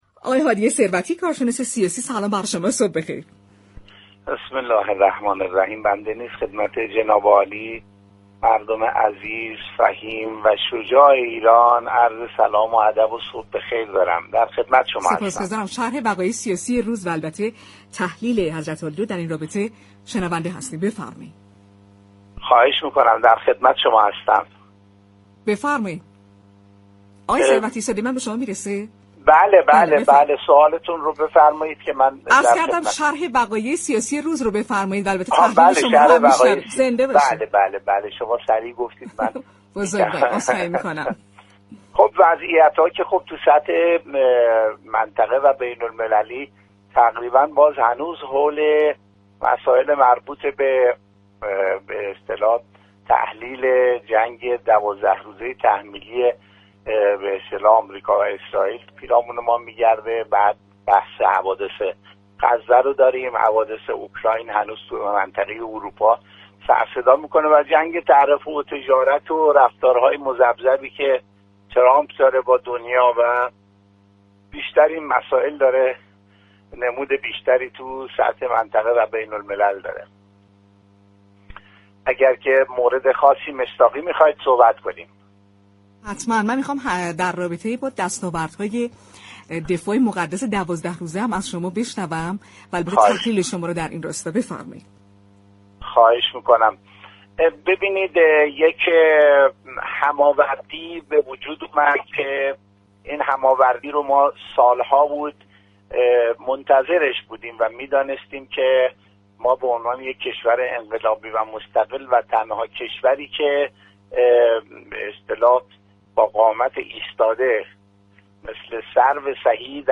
گفت‌وگو با رادیو تهران